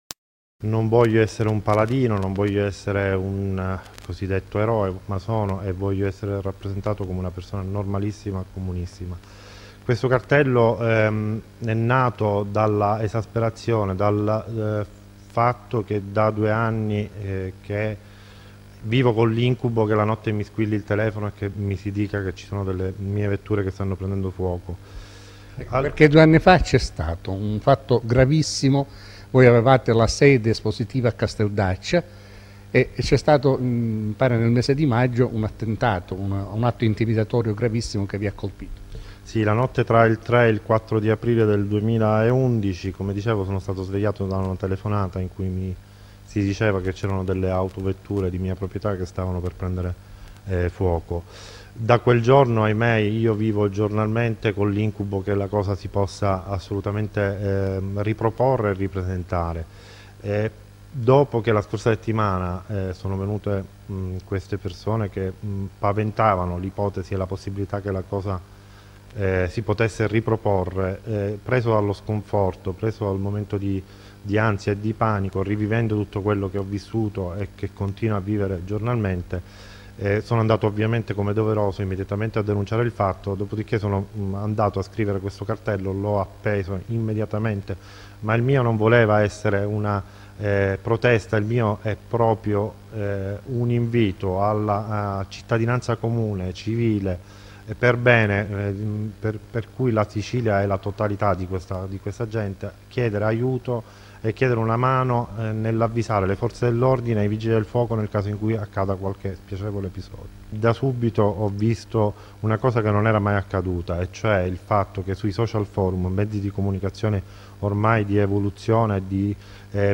ore 17 presso il Centro Esposizione Calicar (via Gelsi Neri, svincolo autostradale di Altavilla Milicia - Pa)